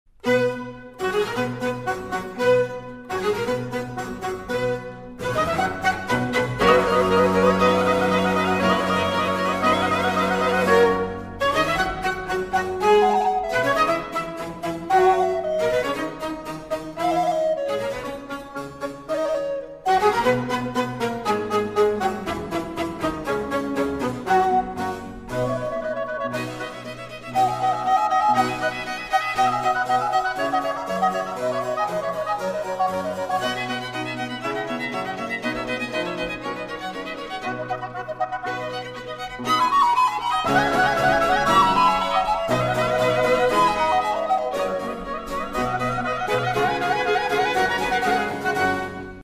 • Качество: 128, Stereo
красивые
без слов
инструментальные
оркестр
классическая музыка